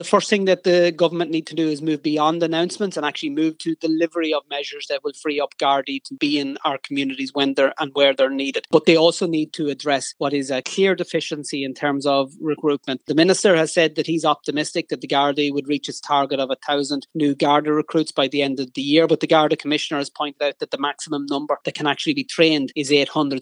Sinn Féin Justice Spokesperson Matt Carthy reports